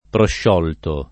prosciogliere [ prošš 0 l’l’ere ]